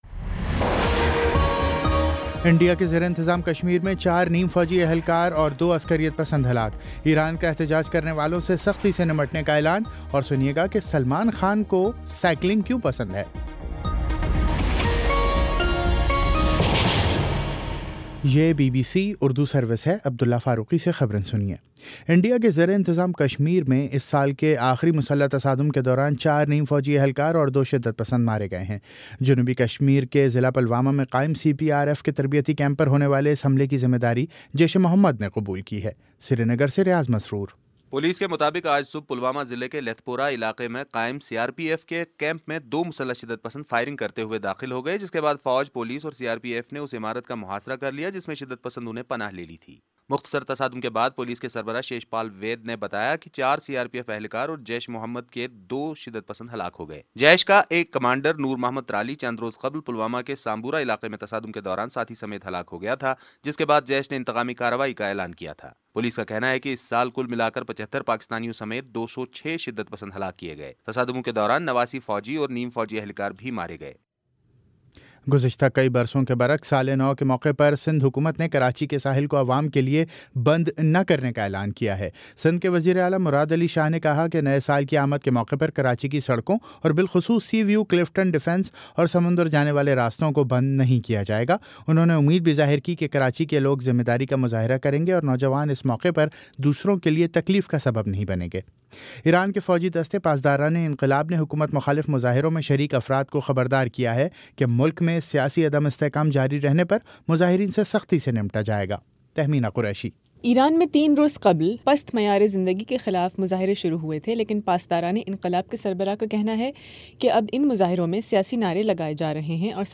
دسمبر 31 : شام چھ بجے کا نیوز بُلیٹن